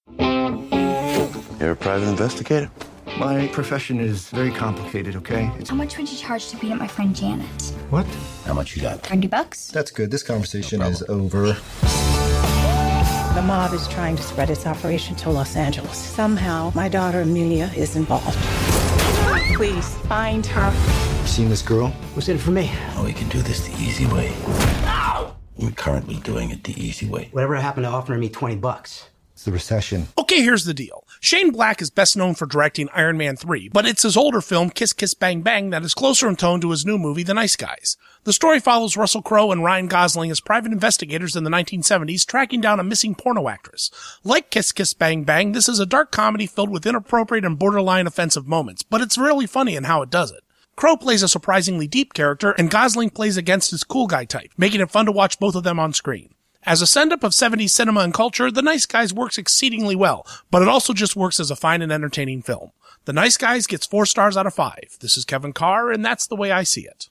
‘The Nice Guys’ Radio Review